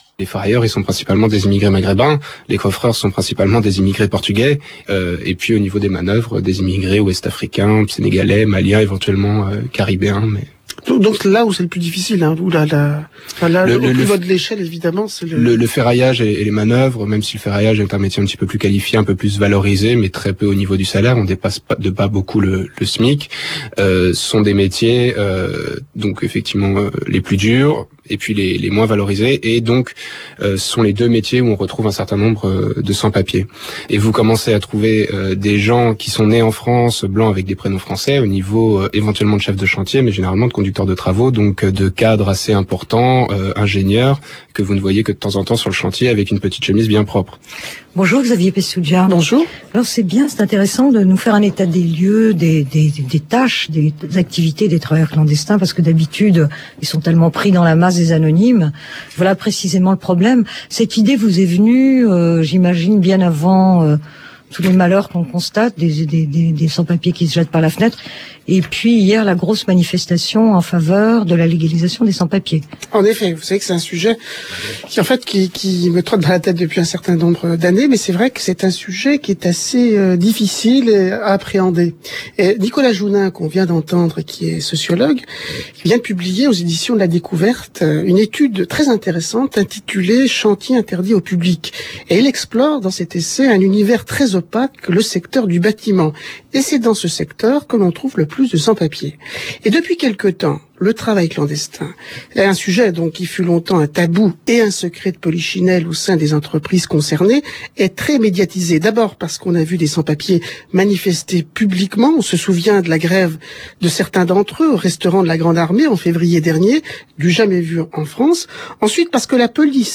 Où un interviewé dénonce une politique raciste et xénophobe… Ah bon ???